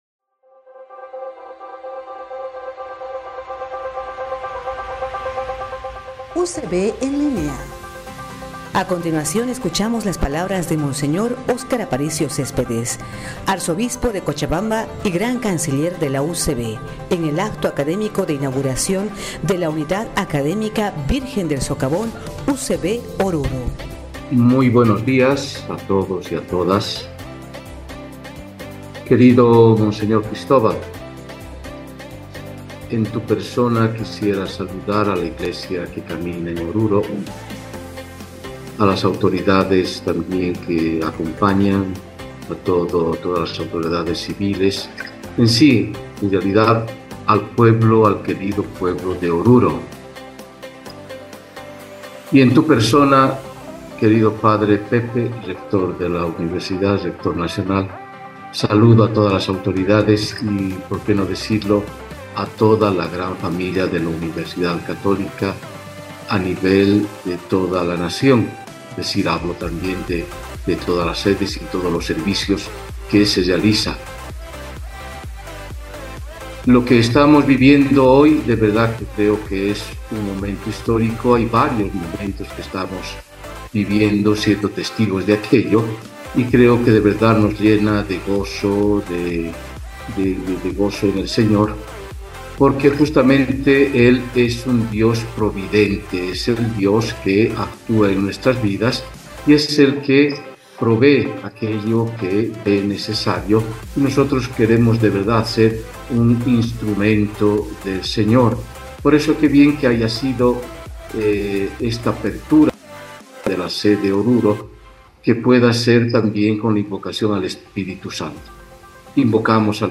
RN 19.09.2023. El pasado 19 de septiembre en el salón de actos de la Diócesis de Oruro se inauguró la Unidad Académica Virgen del Socavón U.C.B. Oruro.